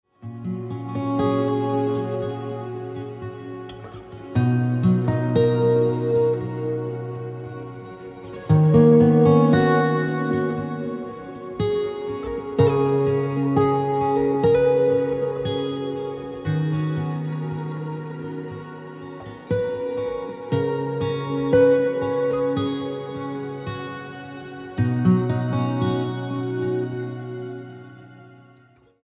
STYLE: Pop
a pleasant and relaxing collection